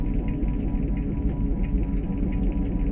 whir.ogg